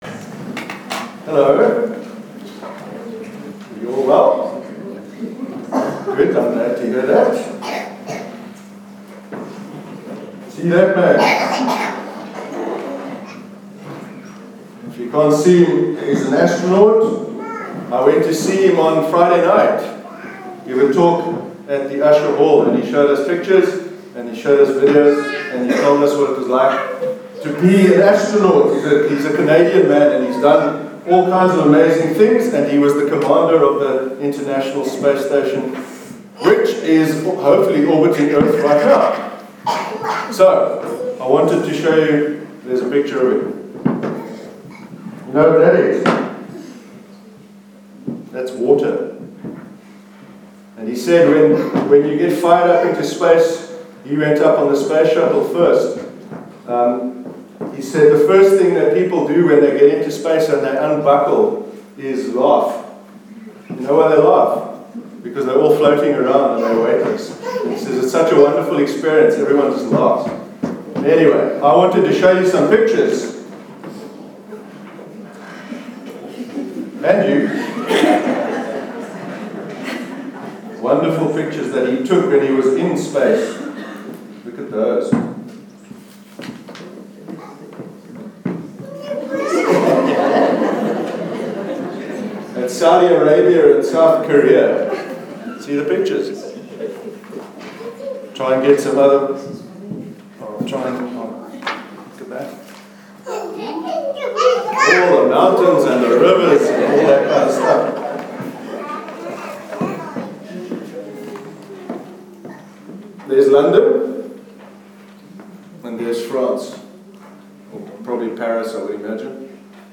Children’s Address- A World Without Barriers
childrens_address_22_jan_20.mp3